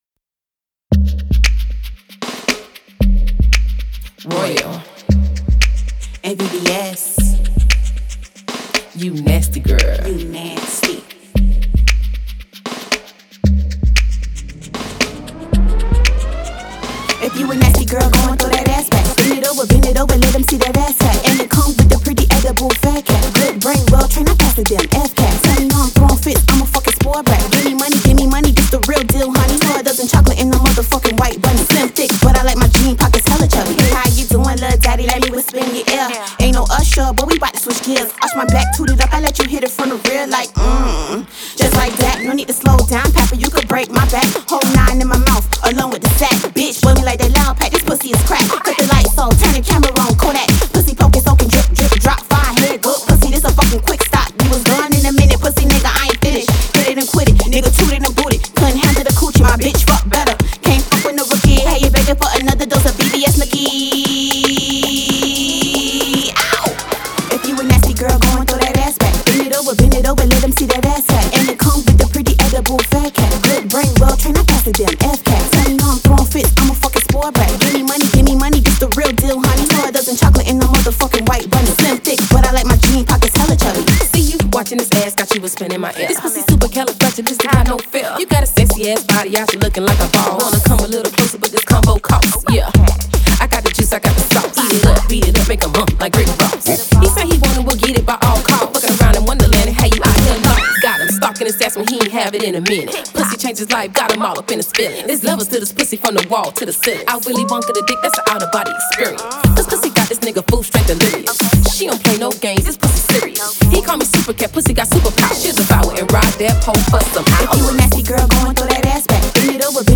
Hiphop
Hip-hop, Pop